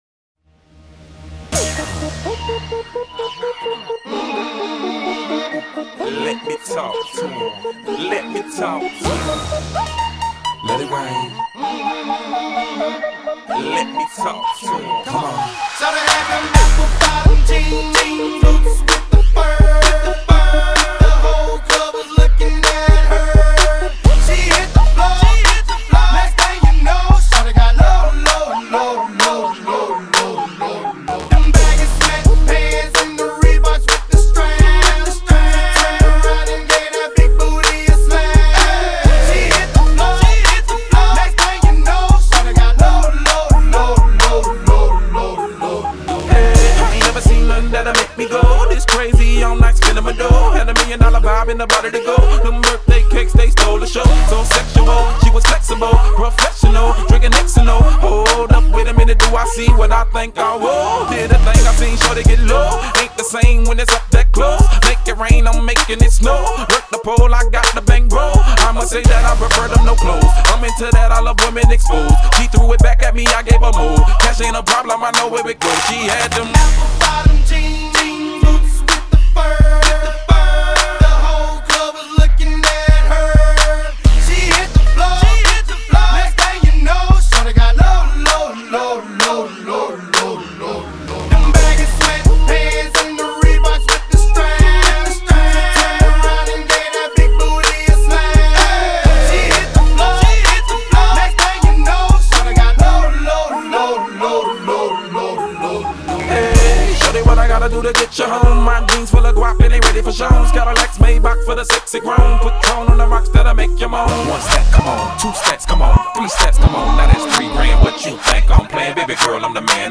Скачать Rap, Hip-Hop альбомы и треки.